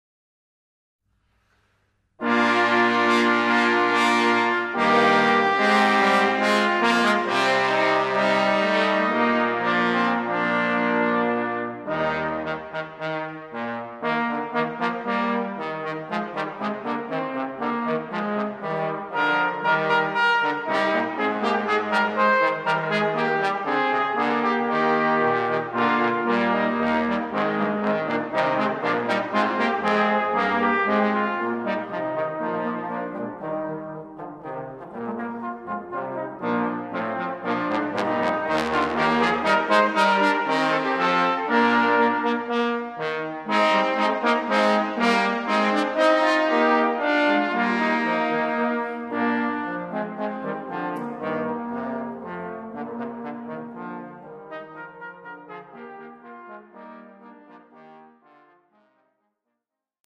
Instrumentalnoten für Posaune